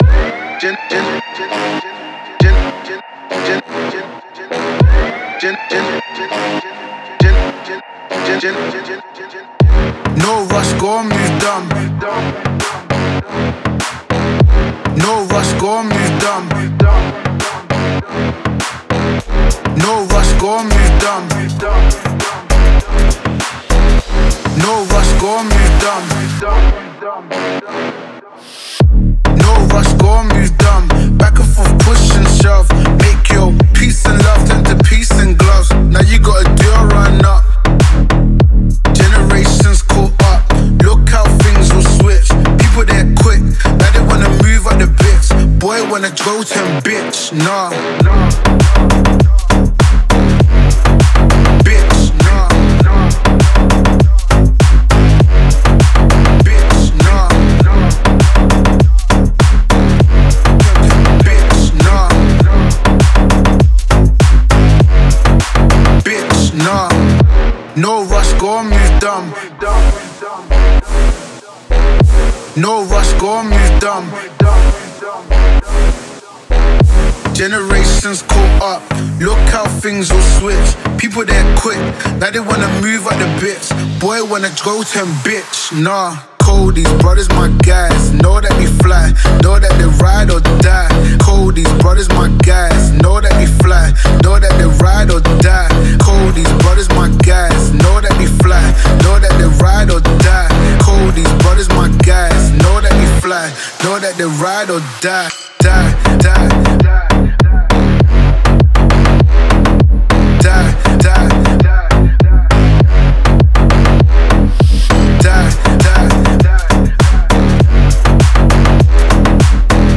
это атмосферная трек в жанре индие-поп